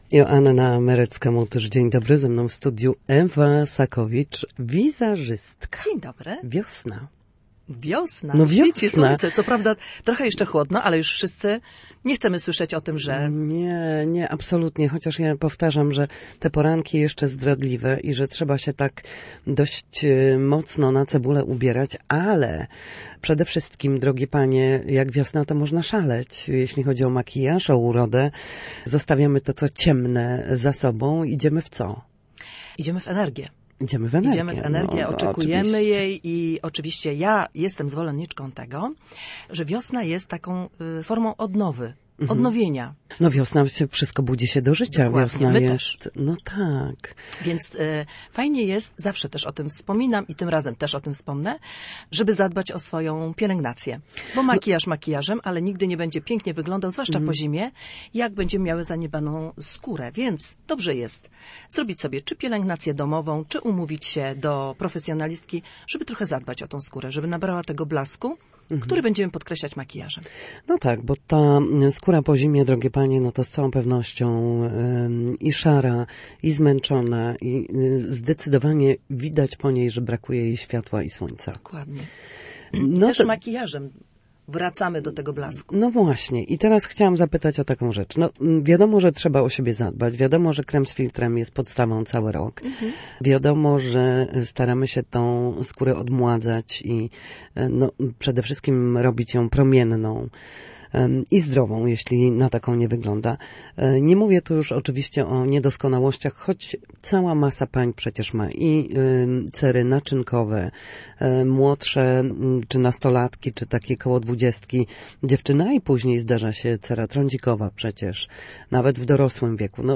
Naturalność i blask. Wiosenne trendy w makijażu w Studiu Słupsk